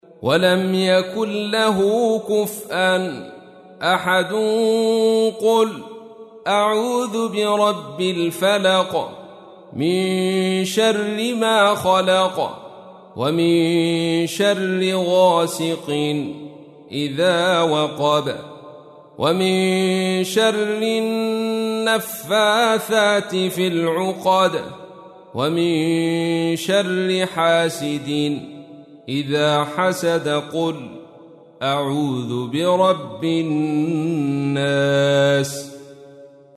تحميل : 113. سورة الفلق / القارئ عبد الرشيد صوفي / القرآن الكريم / موقع يا حسين